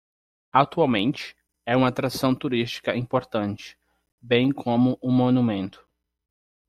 Pronounced as (IPA)
/mo.nuˈmẽ.tu/